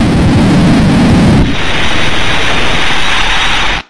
Glitch-Sound1.mp3